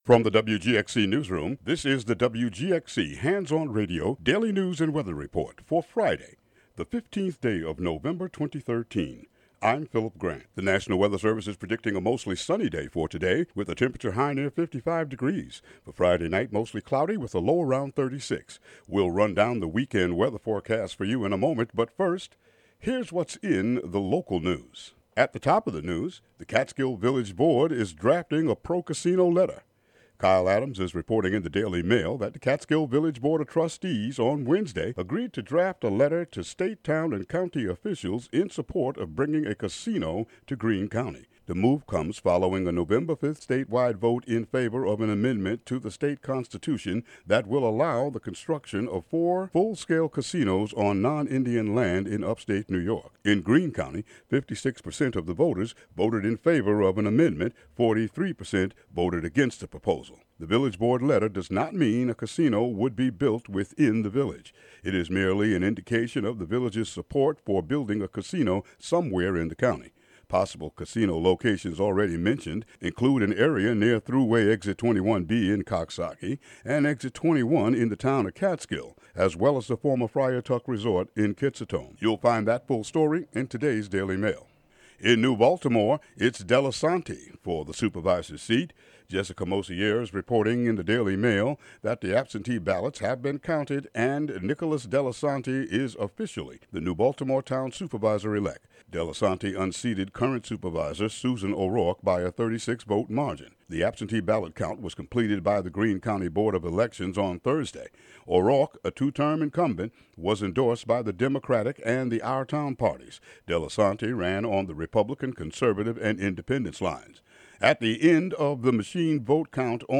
Local news and weather for Friday, November 15, 2013.